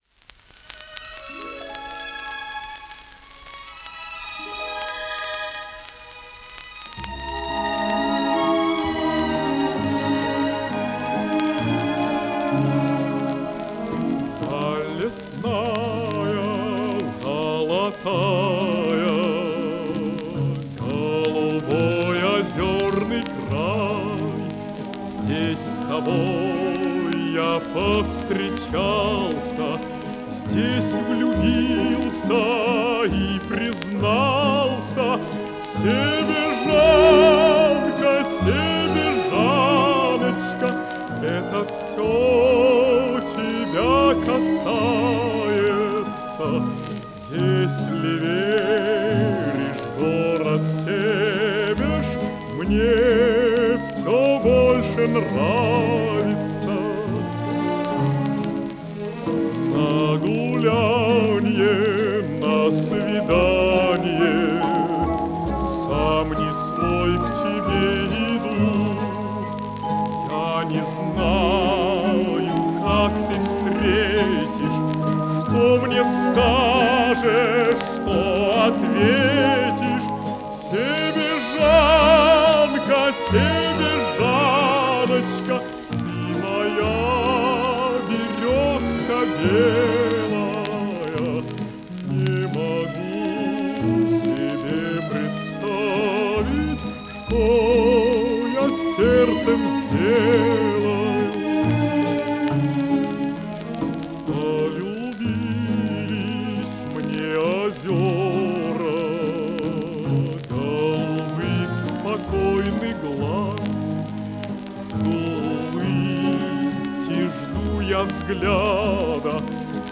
Ваш вариант с шеллака без шумодавов.